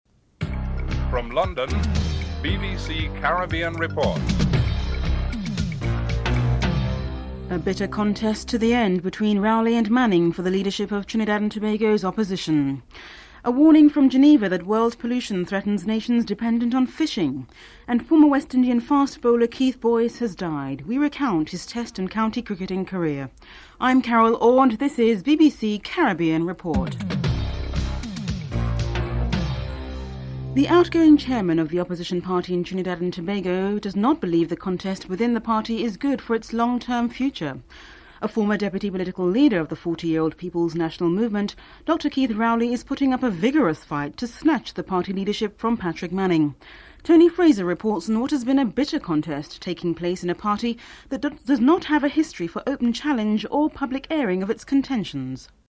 Barbados Ambassador Carlston Boucher is interviewed (02:25-05:28)